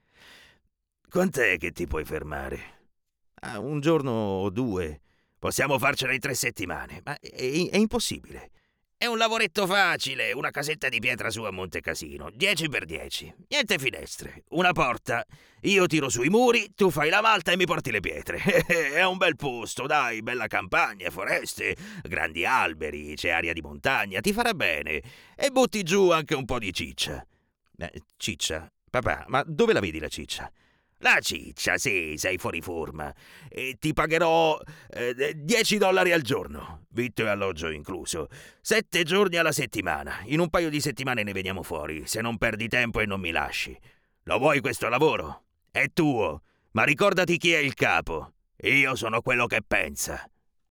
Male
Assured, Authoritative, Character, Corporate, Friendly, Warm, Versatile
Baritone vocal modulation, moderate or "extreme" characterization, at the request of the client, expressions and use of "dialectisms" or accents of various regions and nationalities, make sure that my voice is suitable in any context.
Microphone: RODE NT1 5th Gen - Neumann TLM103